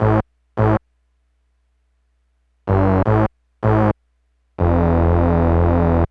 synth04.wav